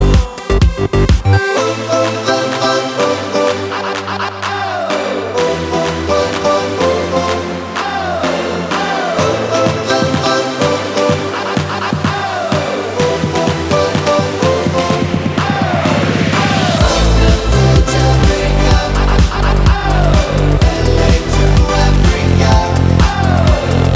Duet Pop (2000s) 3:41 Buy £1.50